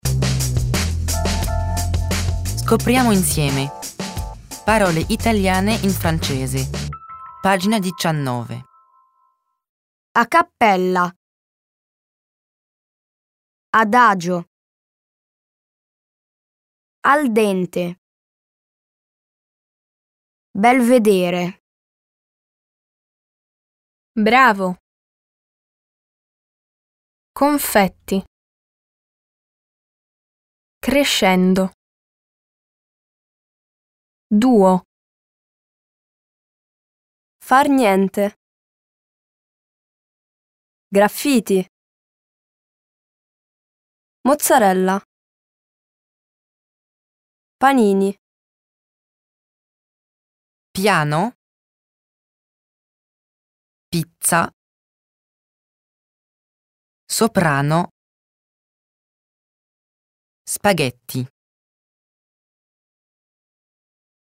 Ecoutez la prononciation italienne des mots proposés avec le fichier audio en pièce jointe.